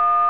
Tone1
TONE1.WAV